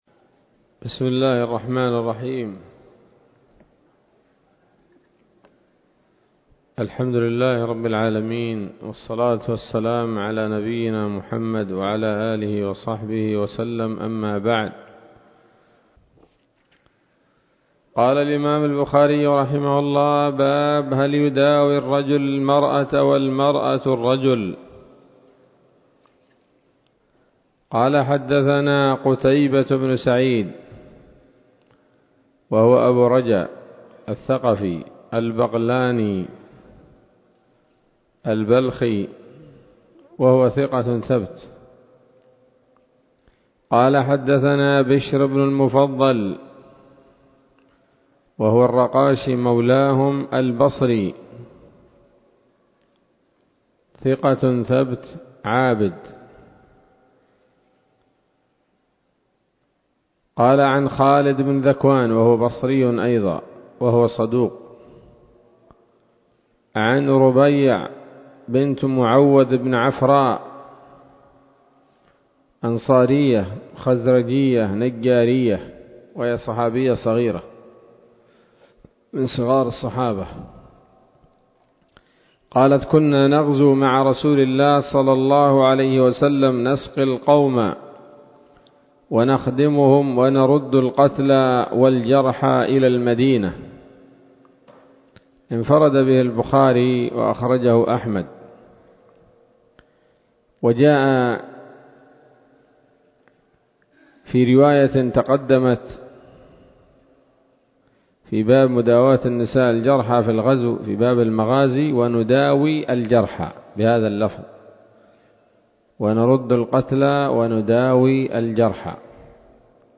الدرس الثاني من كتاب الطب من صحيح الإمام البخاري